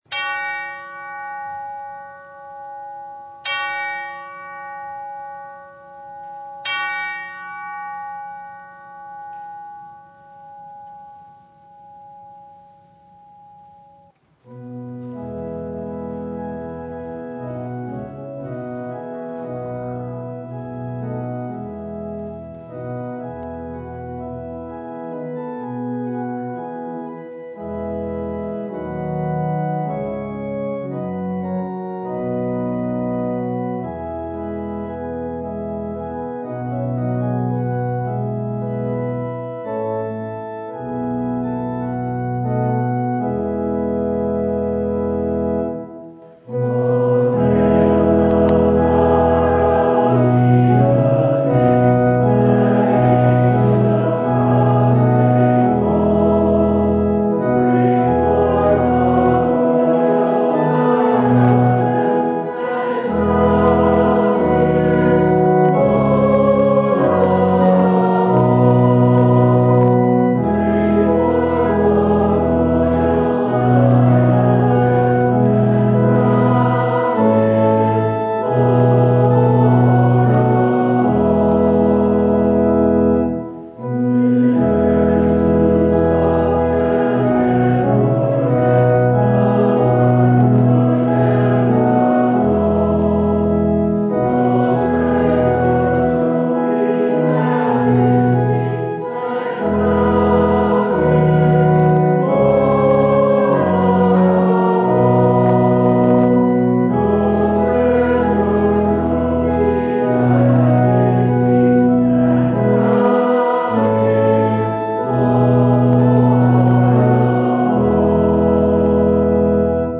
Service Type: The Feast of the Ascension of Our Lord